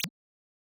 generic-select-softer.wav